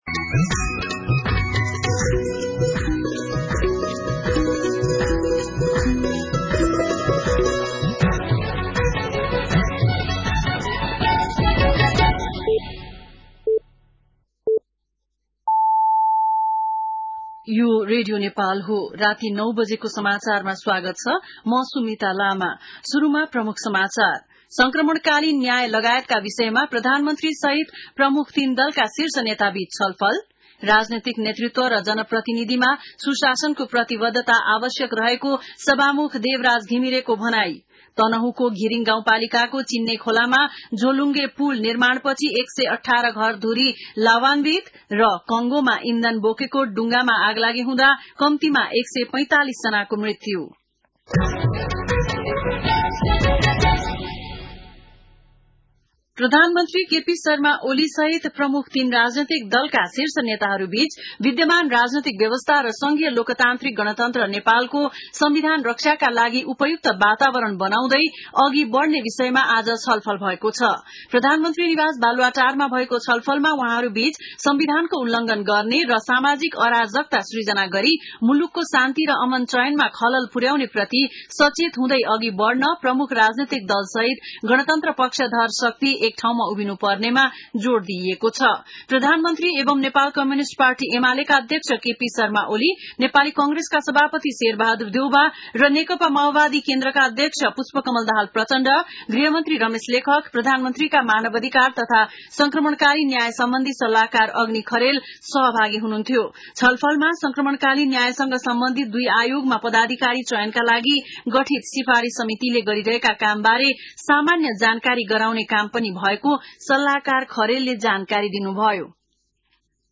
बेलुकी ९ बजेको नेपाली समाचार : ६ वैशाख , २०८२
9-pm-nepali-news-3.mp3